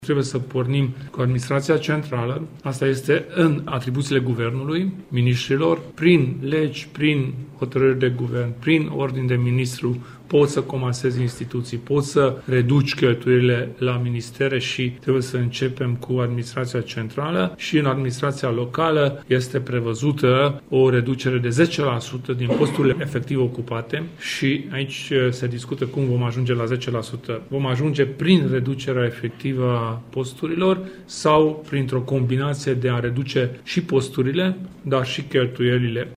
Prezent la Arad, Kelemen Hunor a declarat că Guvernul trebuie să se grăbească cu măsurile de reducere a cheltuielilor în administraţia centrală şi locală, pachet care ar trebui adoptat, din punctul său de vedere, prin angajarea răspunderii în Parlament.
Președintele UDMR a fost prezent, astăzi, la Arad pentru a participa la comemorarea celor 13 generali ai revoluției maghiare de la 1848-49.